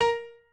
piano2_9.ogg